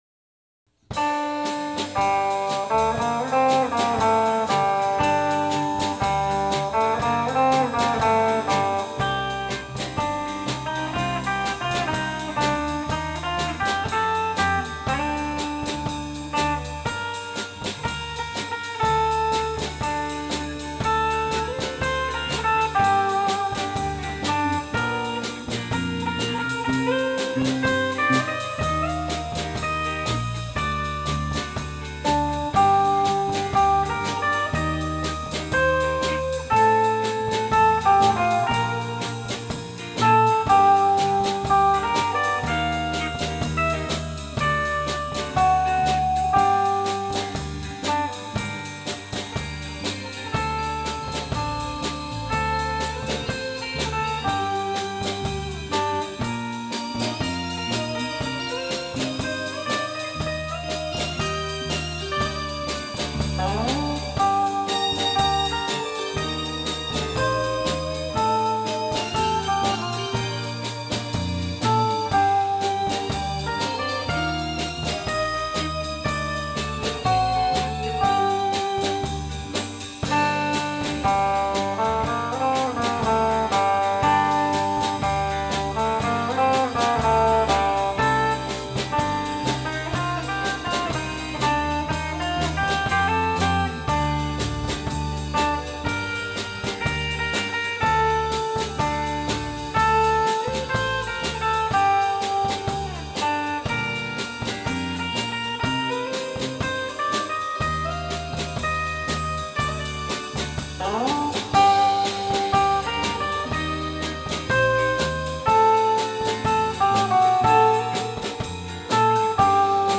2019 X’mas Live